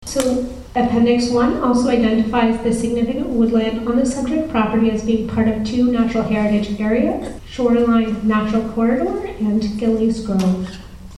The matter was addressed at a Public Meeting of the Committee at the Nick Smith Centre in Arnprior February 5th.